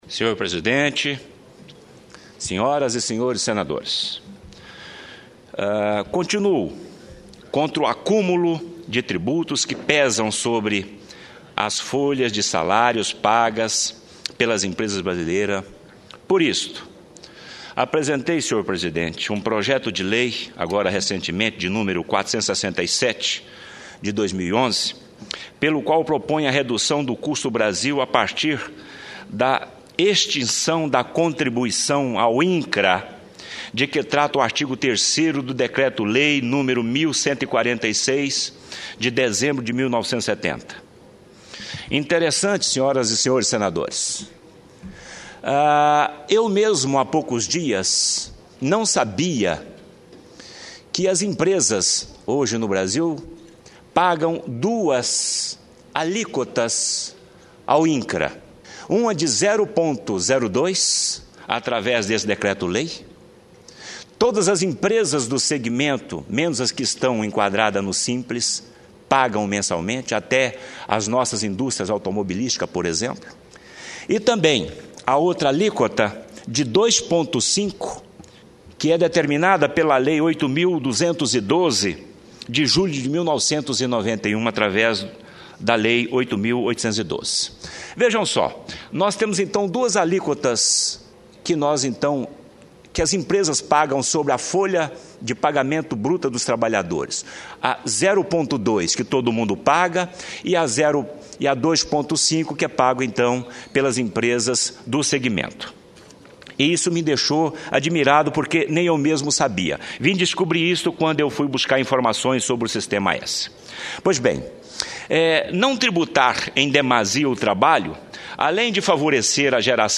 O senador Ataídes Oliveira (PSDB-TO) disse ser a favor da desoneração da folha de salários das empresas brasileiras. Por isso defendeu projeto de sua autoria que propõe a a redução do Custo Brasil a partir da extinção da contribuição paga ao Incra por parte de empresas que nada tem a ver com o setor agrário.
Plenário